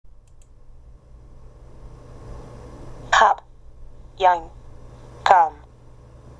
[ ア ] cup, young, come